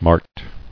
[mart]